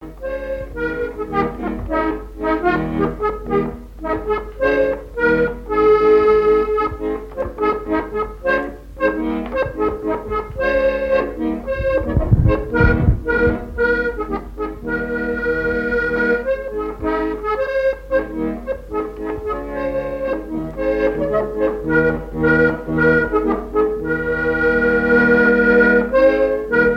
Air d'une chanson du music-hall
Pièce musicale inédite